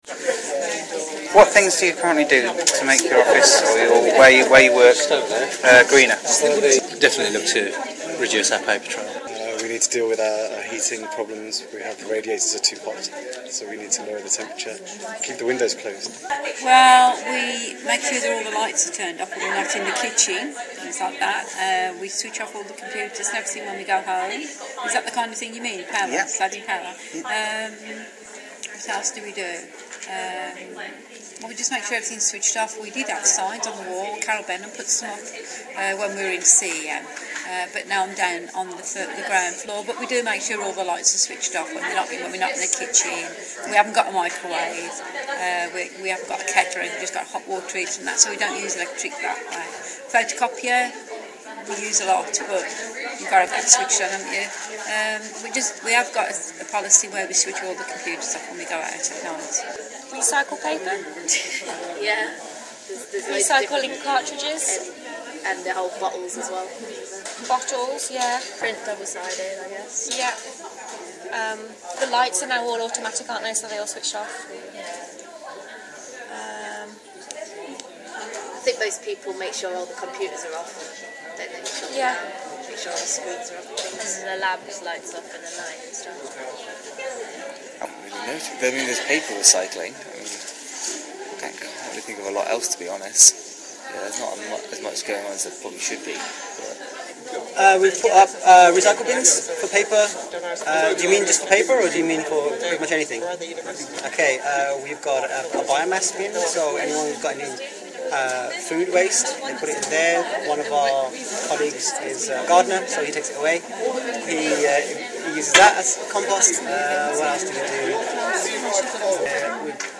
REcording from Environment day at the Small staff commonroom, 25th Jan2013